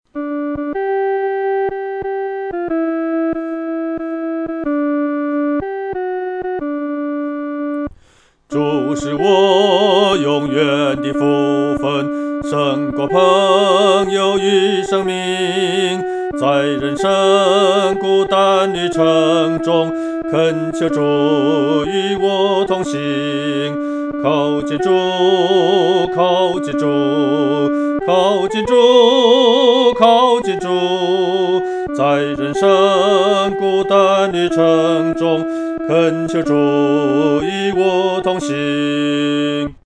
独唱（第二声）
靠近主-独唱（第二声）.mp3